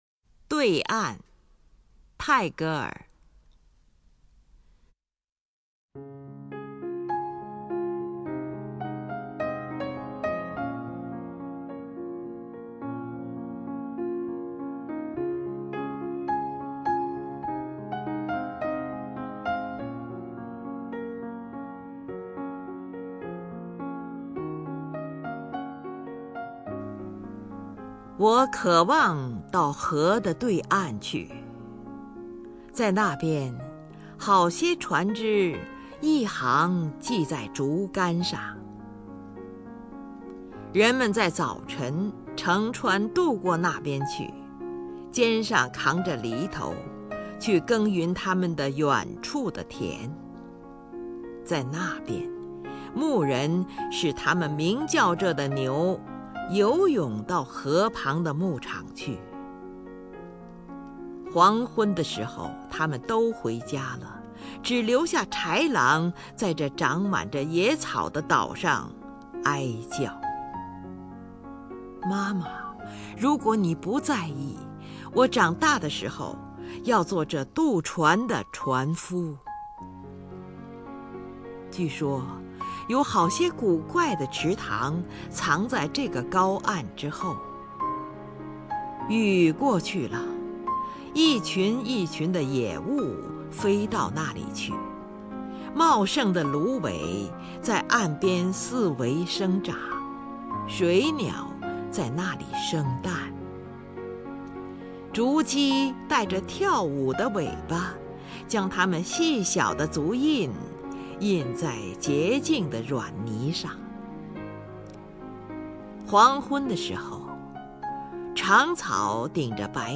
首页 视听 名家朗诵欣赏 林如
林如朗诵：《对岸》(（印度）拉宾德拉纳特·泰戈尔)